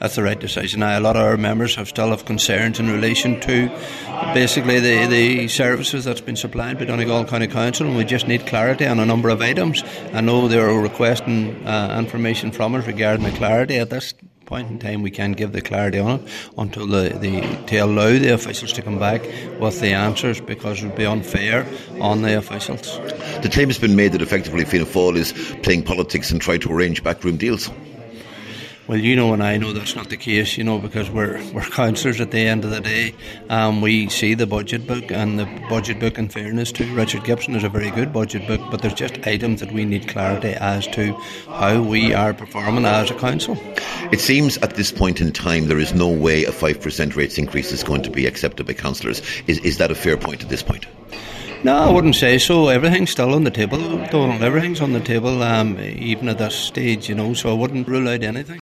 Cathaoirleach Cllr Paul Canning says members now have 12 days to have their concerns addressed.